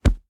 punch3.ogg